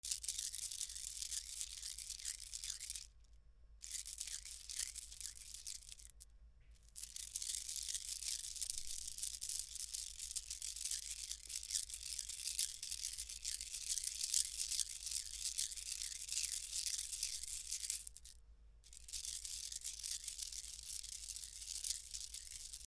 数珠をする
C414